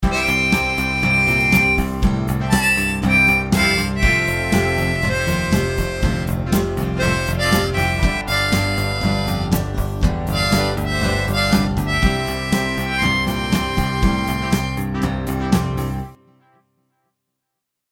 Today I’m experimenting with GarageBand on my iPad.
I first laid down a drum track and then added an acoustic guitar chord progression of C, F, and G. That sounded nice.
Finally I also added a plucked bass using the Smart Bass instrument.
I then used my USB Blue Snowflake microphone to record the harmonica track.